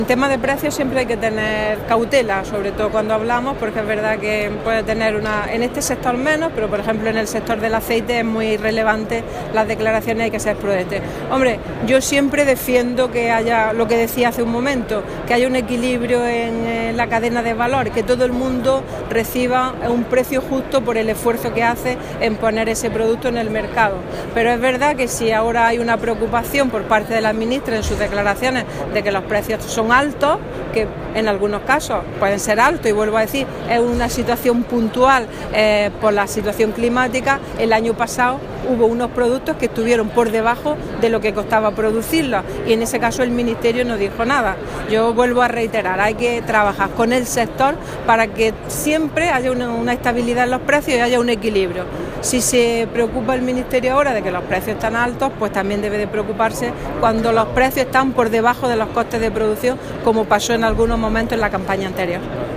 Declaraciones de Carmen Ortiz sobre producción hortofrutícola andaluza